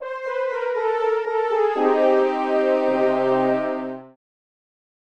game_lost.mp3